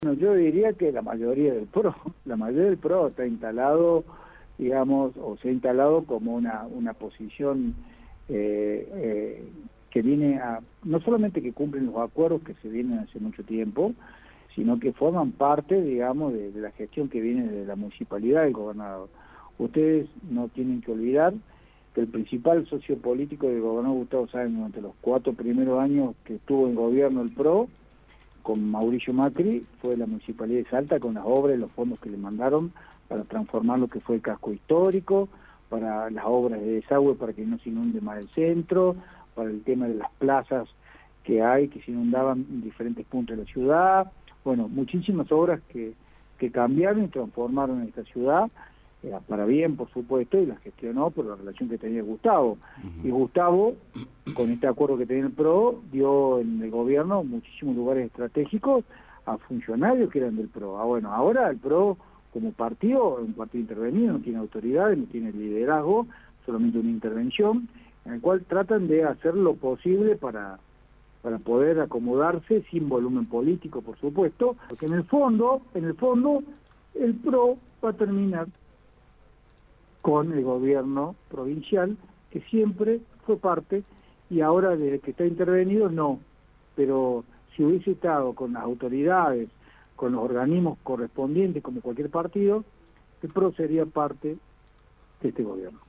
A través de una entrevista radial, el titular del REMSa se despachó contra JxC, pero recordó la cercanía que tuvo Sáenz con Mauricio Macri allá por el 2017.